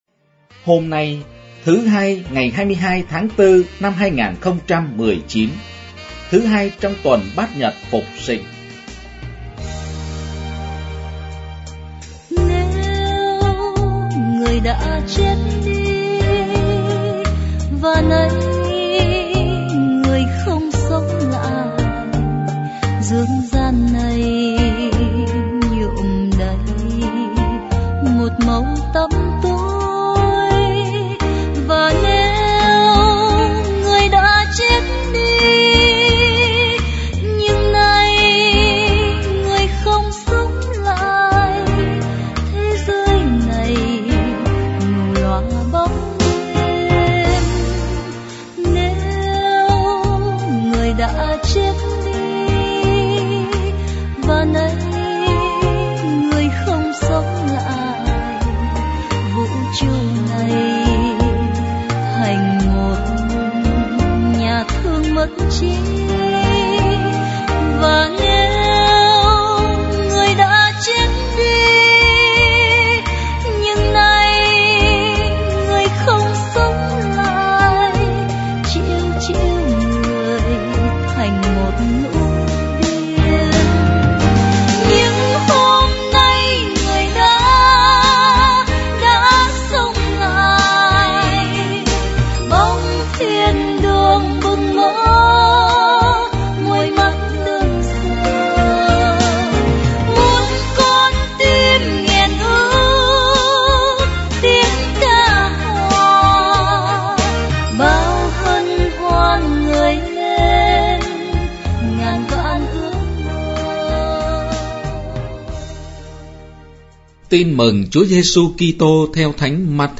Nghe suy niệm Lời Chúa